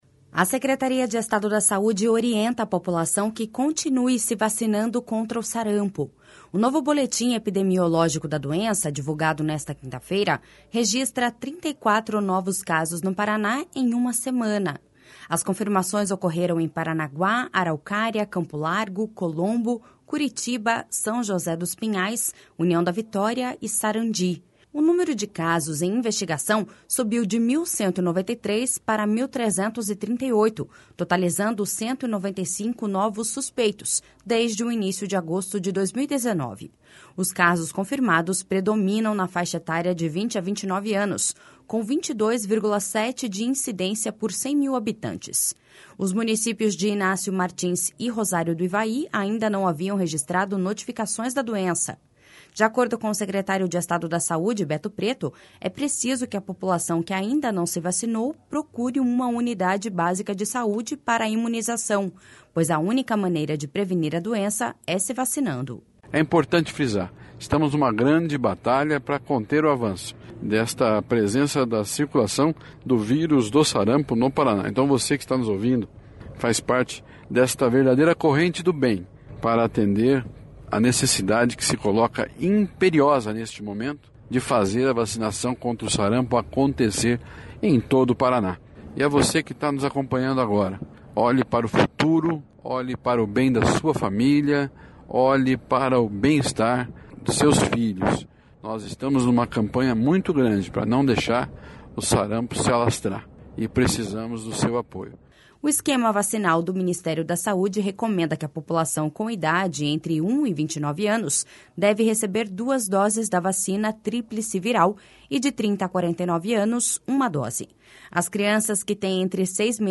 De acordo com o secretário de Estado da Saúde, Beto Preto, é preciso que a população que ainda não se vacinou procure uma unidade básica de saúde para imunização, pois a única maneira de prevenir a doença é se vacinando.// SONORA BETO PRETO.//